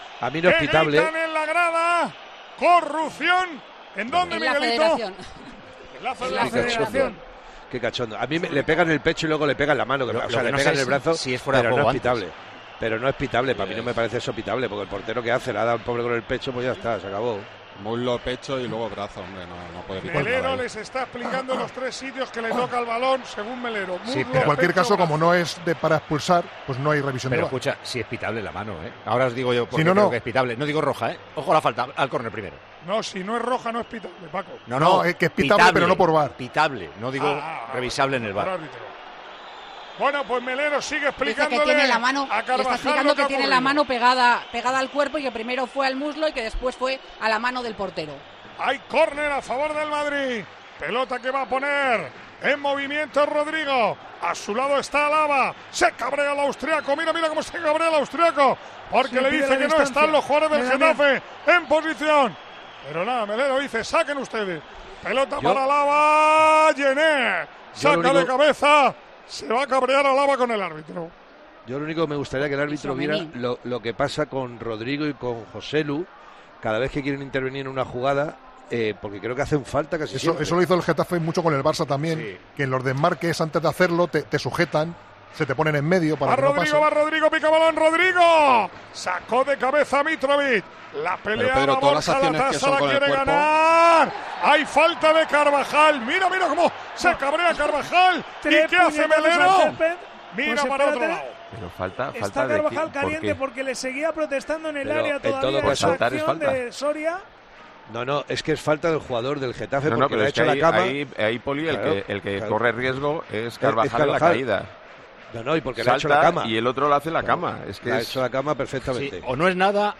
Una parte de la grada del Santiago Bernabéu ha iniciado un cántico que ha obligado a Manolo Lama a detener su narración del partido
El Bernabéu grita: “Corrupción… ¿dónde?”
“Qué cachondos” se limita a decir Poli Rincón al escuchar la pregunta de Lama, quien detiene su narración del Real Madrid-Getafe para destacar el cántico que capta desde la cabina de comentaristas de la Cadena COPE.